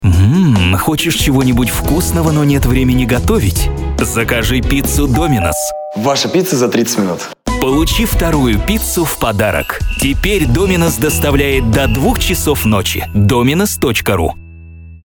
Аудиореклама для пиццерии Domino’s Pizza